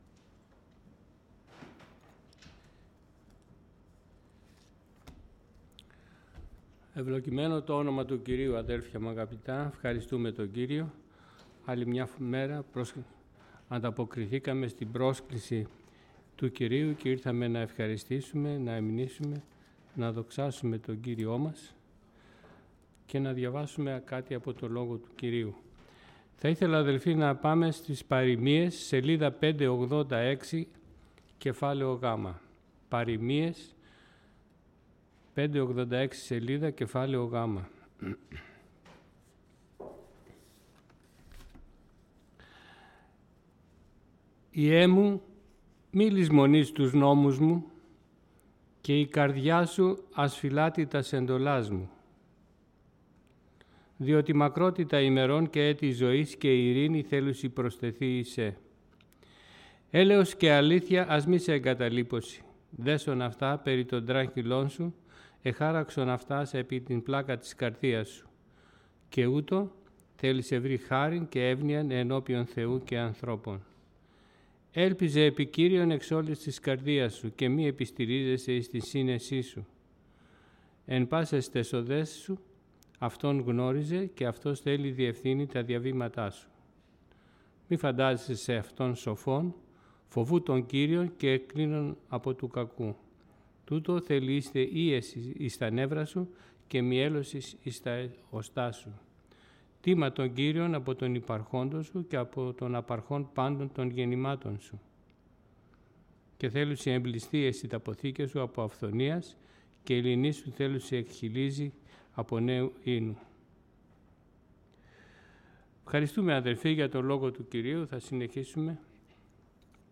Series: Κήρυγμα Ευαγγελίου